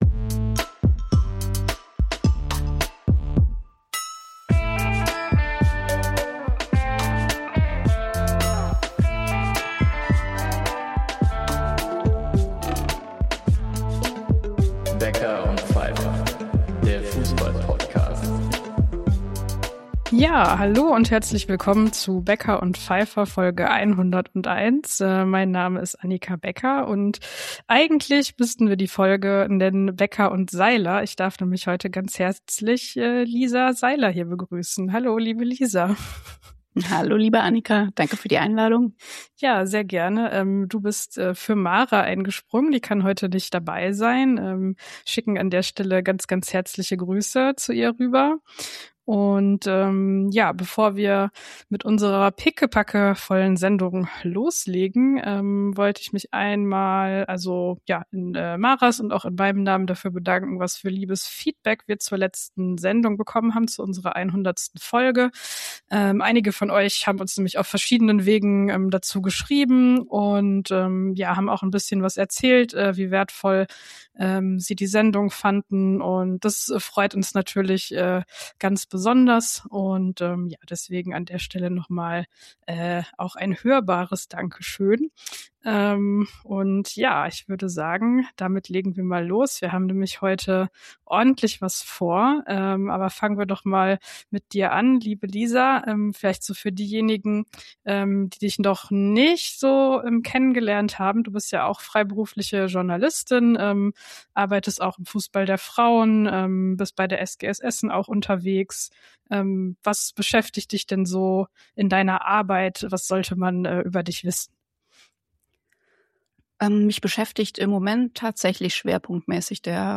Wir sind zurück aus unserer kurzen Sommer-Podcast-Pause und nehmen zwischen Veranstaltungen und beruflichen Reisen Folge 52 in einem Kölner Hotelzimmer auf. Zu besprechen gibt es allerlei, da wären einerseits die Spiele der Mainzerinnen gegen die Bochumerinnen um den Aufstieg in die 2. Bundesliga, der letztendlich den Frauen des VfL gelang.